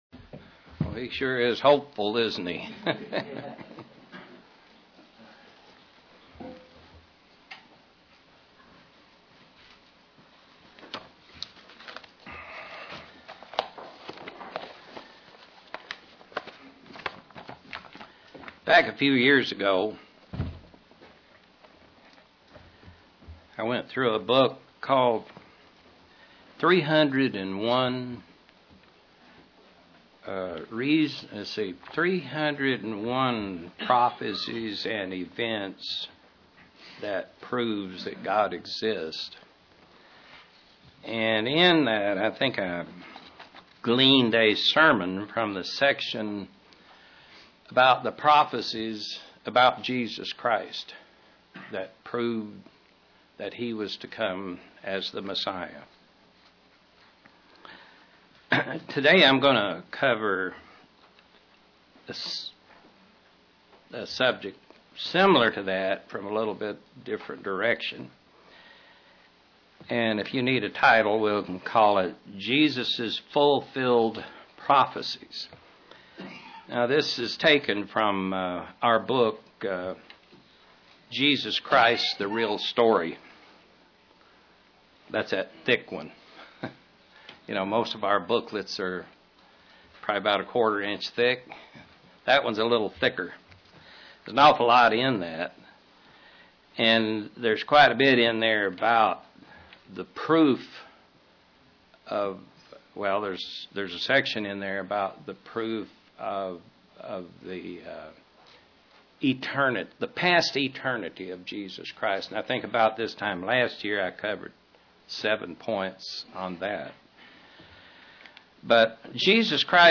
Print The scriptural outline of the fulfilled prophecies of Christ UCG Sermon Studying the bible?